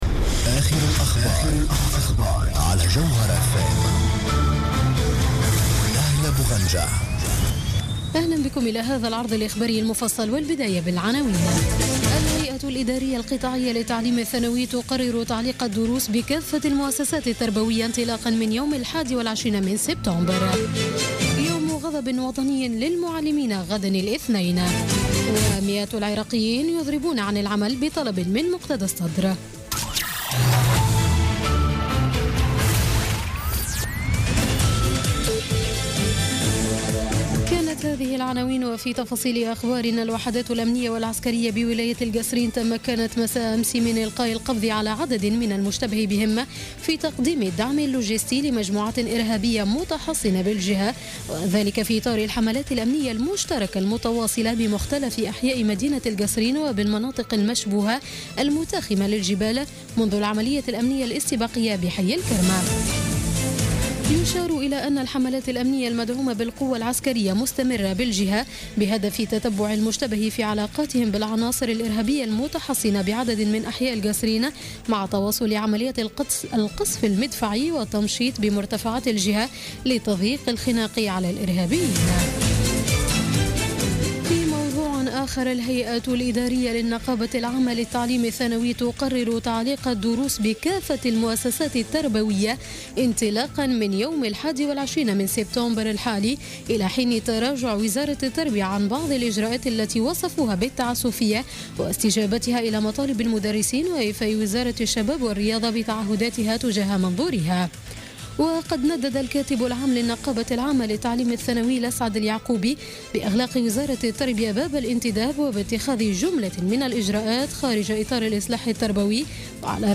نشرة أخبار السابعة مساء ليوم الأحد 4 سبتمبر 2016